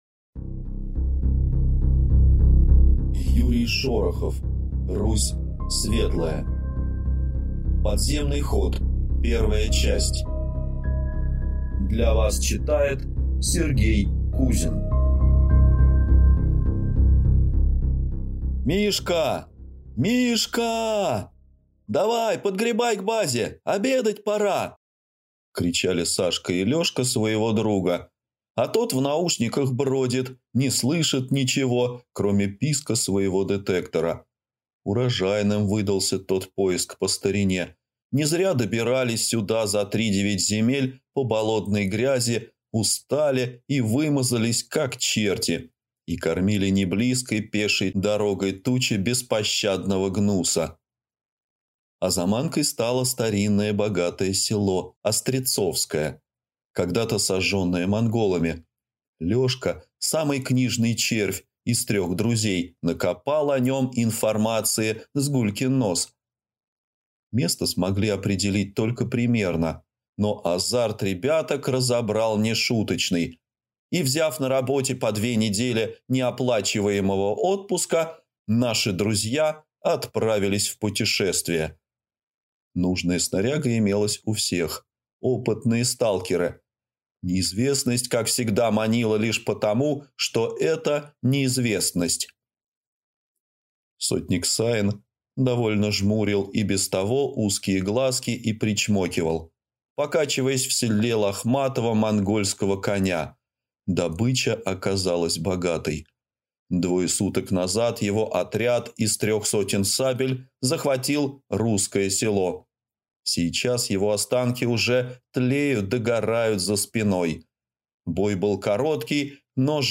Аудиокнига Русь светлая | Библиотека аудиокниг